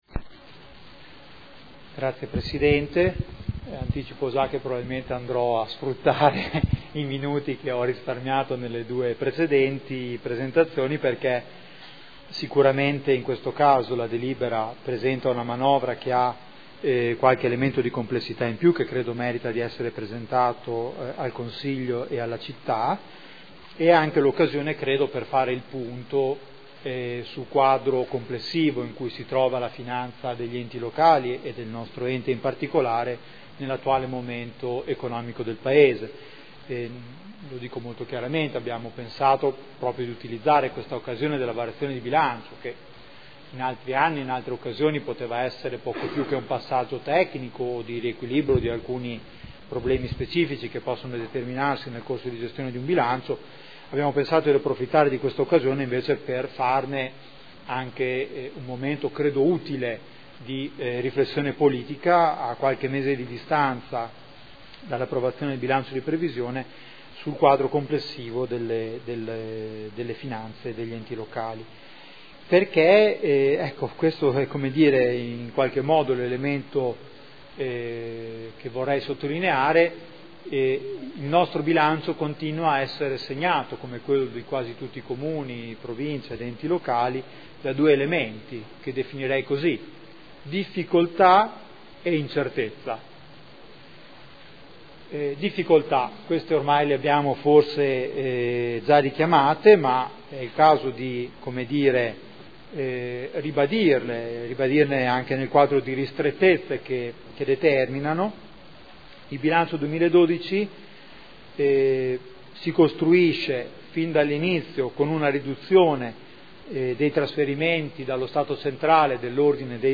Giuseppe Boschini — Sito Audio Consiglio Comunale
Seduta del 27/09/2012.